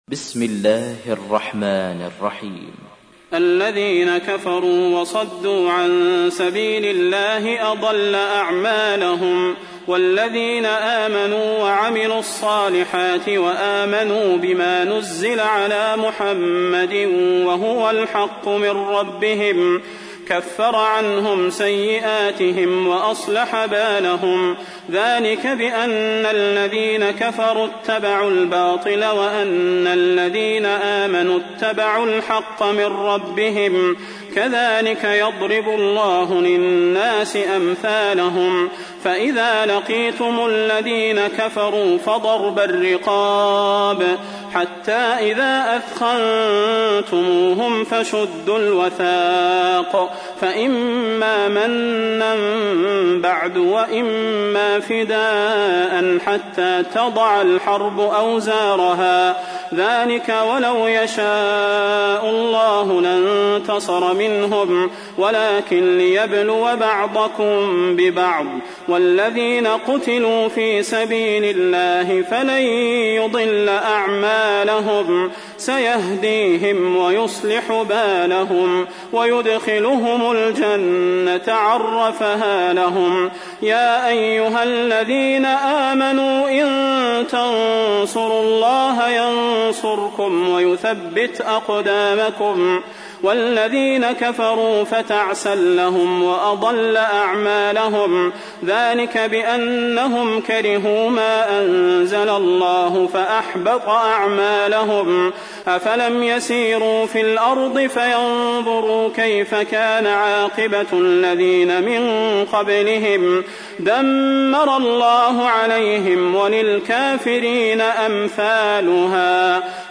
تحميل : 47. سورة محمد / القارئ صلاح البدير / القرآن الكريم / موقع يا حسين